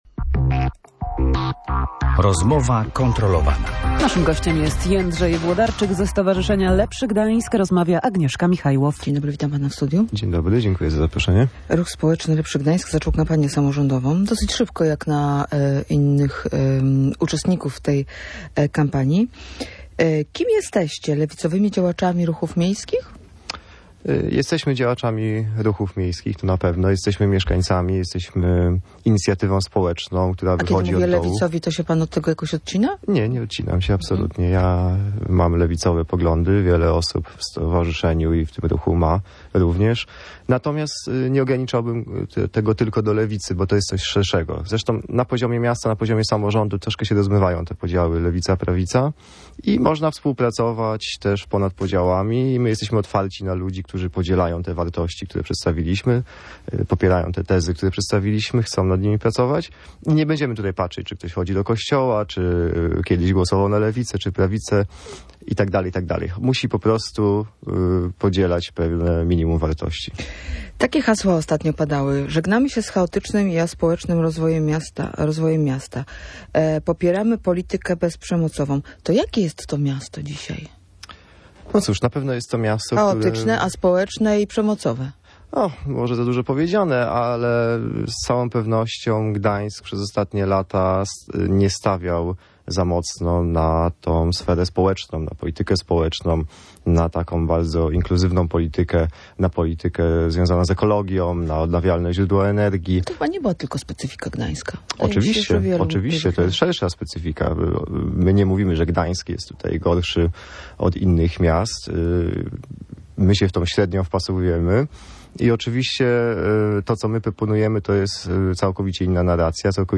mówił w Radiu Gdańsk